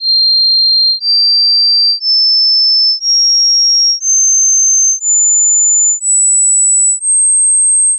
音階による再生機器と耳の音域チェック
※3. 音声ファイルはサイン波で、mp3とwav形式を使用しています。
09_c8_c9_sine.wav